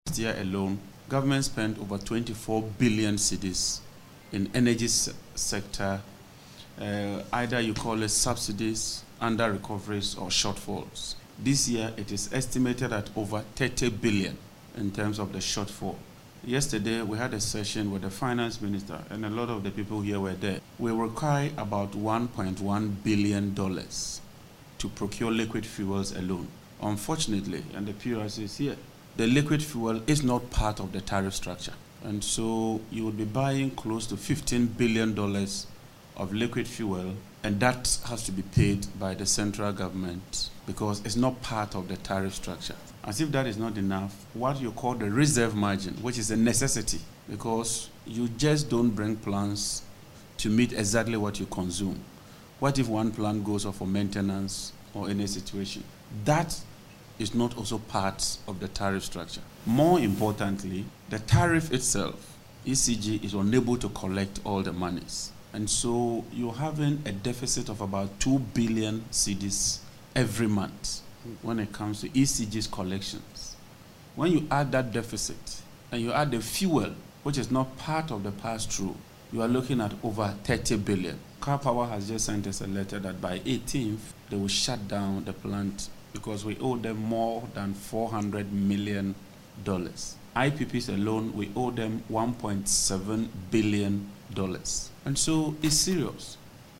Speaking before the Parliamentary Committee on Energy, Mr Jinapor stated that the financial strain is so severe.